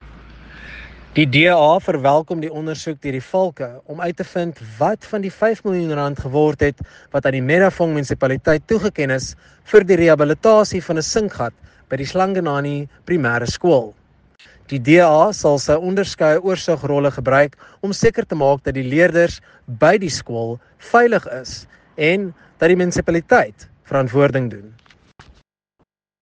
Note to Editors: Plesae find English and Afrikaans soundbites by Nicholas Gotsell MP here, and